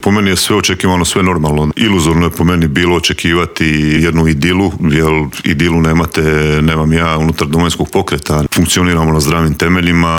ZAGREB - Premijer, ministri, šefovi oporbenih stranaka, gradonačelnici - svi oni bili su gosti Intervjua tjedna Media servisa.